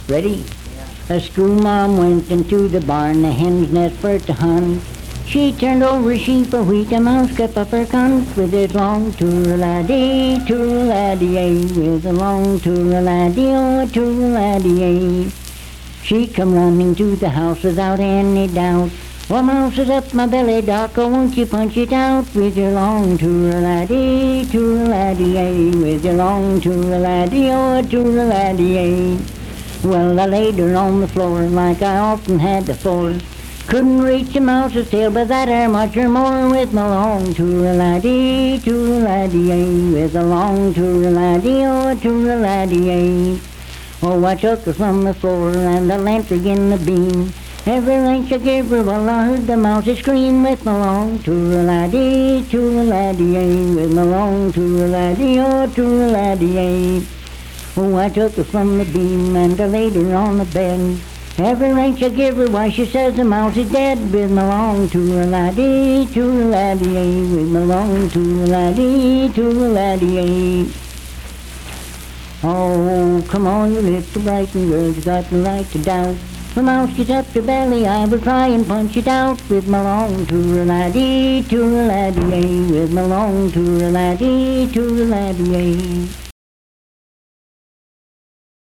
Unaccompanied vocal music
Performed in Sandyville, Jackson County, WV.
Folk music--West Virginia, Ballads
Bawdy Songs
Voice (sung)